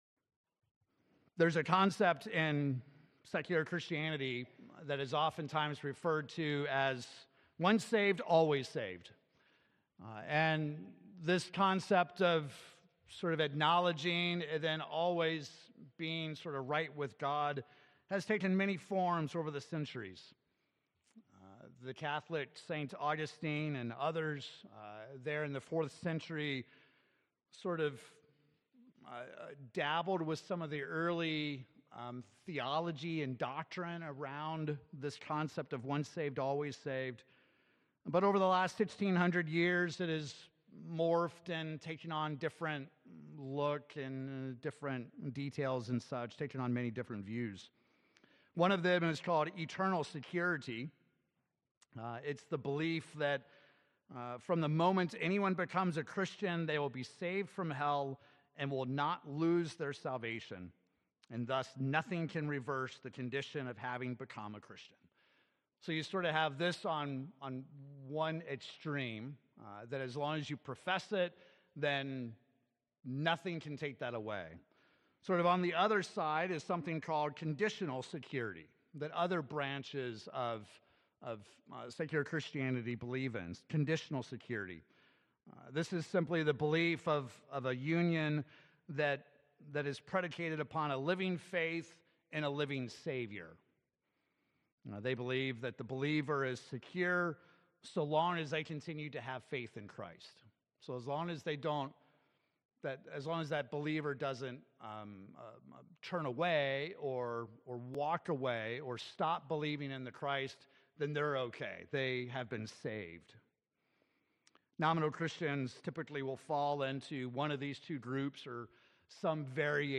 The Bible is very clear in explaining God's plan for offering salvation to mankind. In this sermon we examine God's plan for saving WHO and WHEN by considering some of Paul's writings to the church at Corinth, as well as the thief who died next to Jesus.